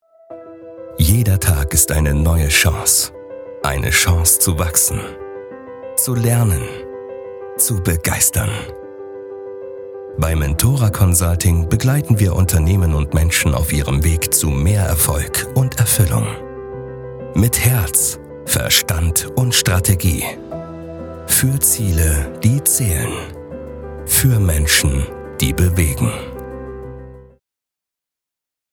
• Eigenes Tonstudio
Imagefilm - emotional inspirierend
Imagefilm-emotional-inspirierend.mp3